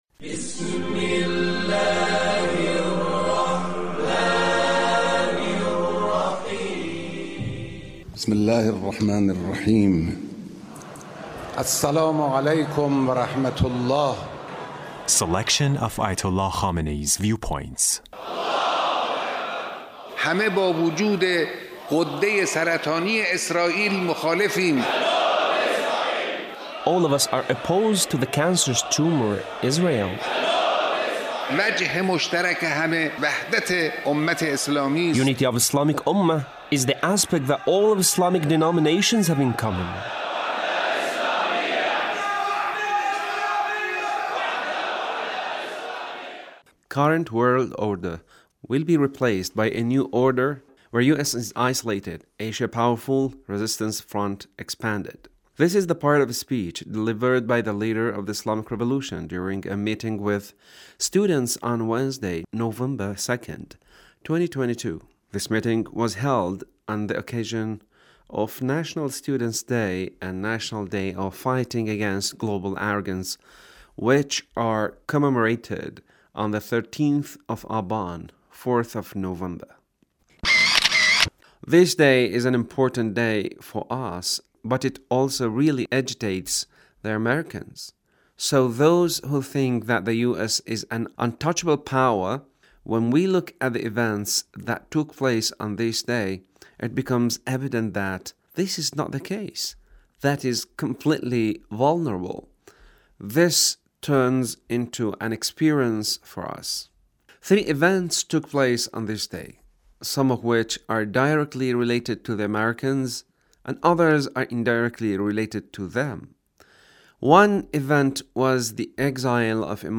Leader's Speech on 13th of Aban